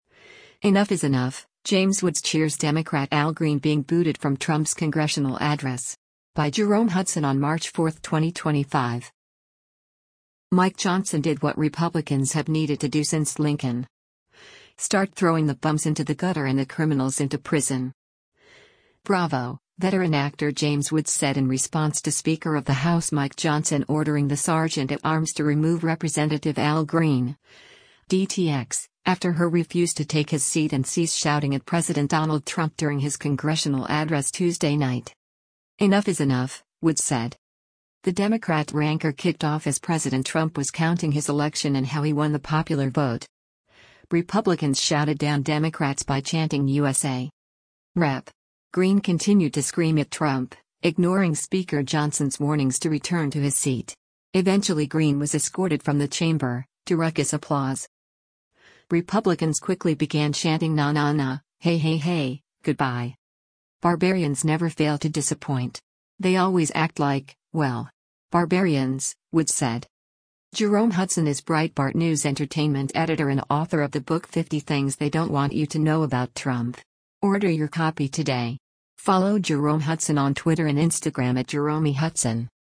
WASHINGTON, DC - MARCH 04: Rep. Al Green (D-TX) shouts out as U.S. President Donald Trump
Republicans shouted down Democrats by chanting “USA!”
Rep. Green continued to scream at Trump, ignoring Speaker Johnson’s warnings to return to his seat. Eventually Green was escorted from the chamber, to ruckus applause.
Republicans quickly began chanting “na na na, hey hey hey, good-bye!”